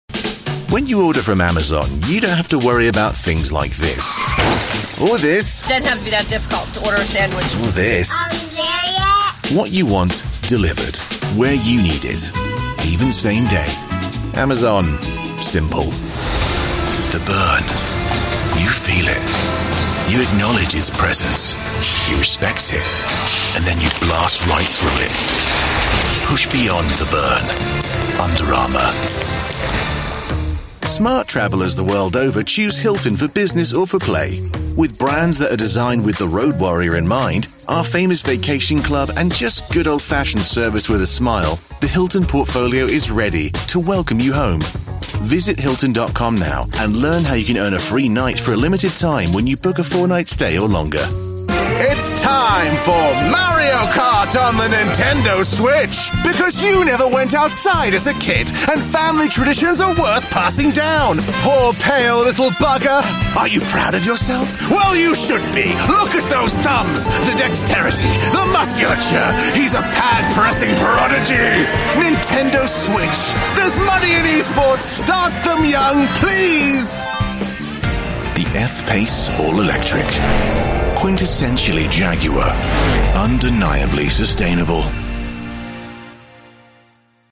If you’re looking for a smooth British voice as well as other accents (If that’s your cup of tea), look no further.
COMMERCIAL 💸
conversational
smooth/sophisticated